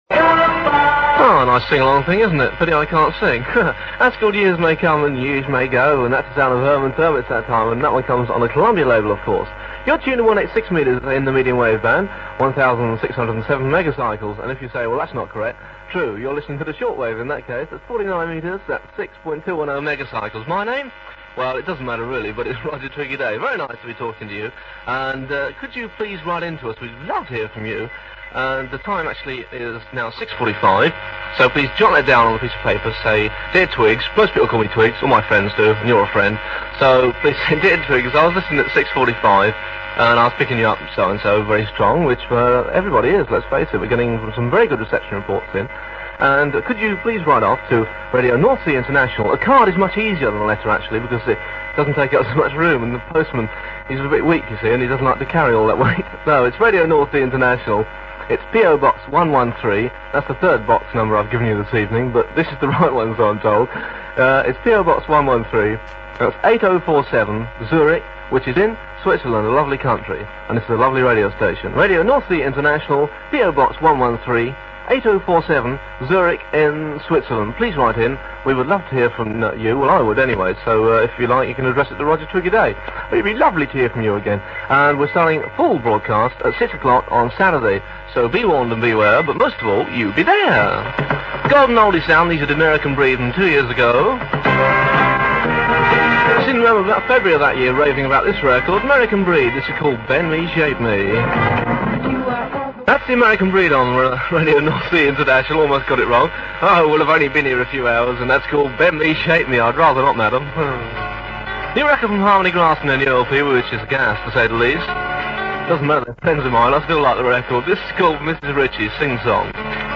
Morse code was audible in the background of the medium wave transmissions across much of the reception area.